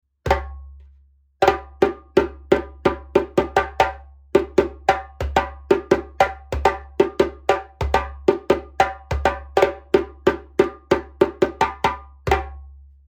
ボディ ギニア 打面 34cm、高さ60cm、重量6kg 木材 ドゥグラ ヤギ皮 マリ（中厚皮） 縦ロープ：ブラック＆グリーン 5mm made in Japan クレードルロープ：ブラック 4mm made in USA 足ゴム（ラバープロテクション）付き
持ち運びも楽チンな重さ6キロ、それでいて34センチ口径なので音のレンジも広く、３音出しやすいです。